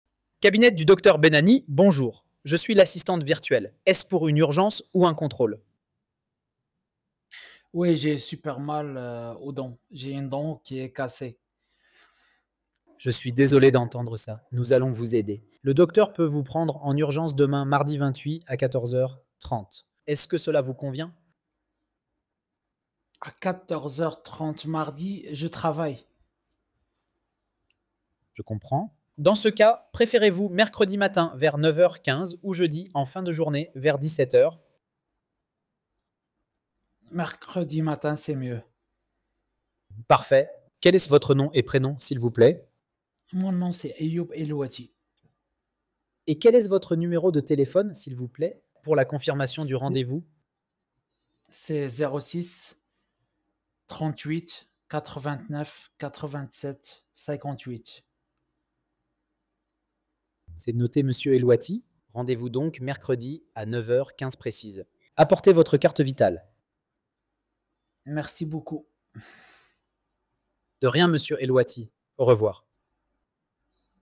Écoutez notre IA.
Audio-pour-la-conversation-Cabinie.mp3